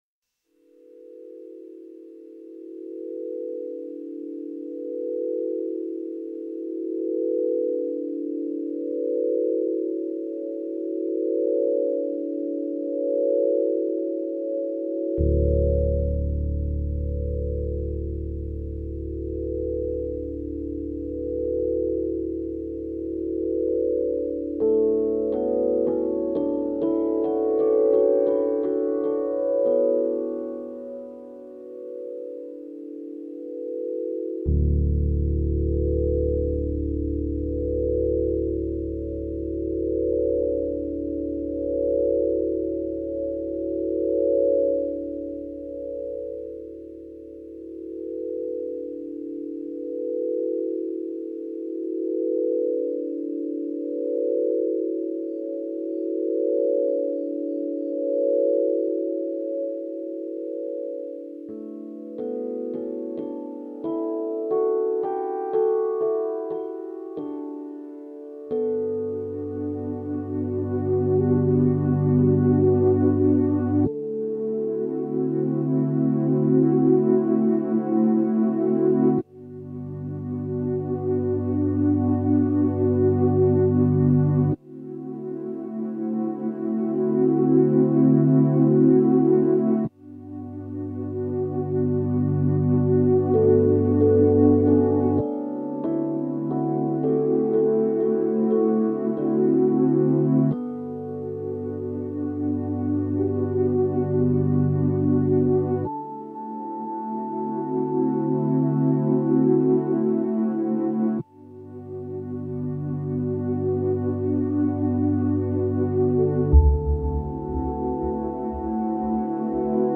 ripply water tunes